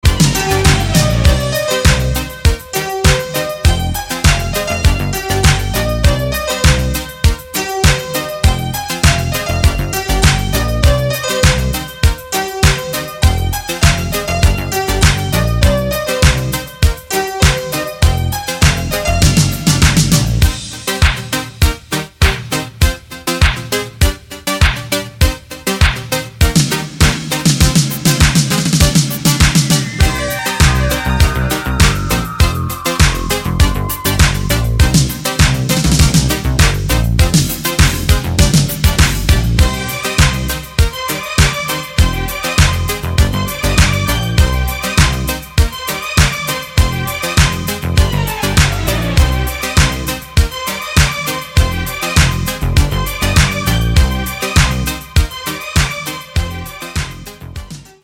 громкие
remix
Electronic
без слов
пианино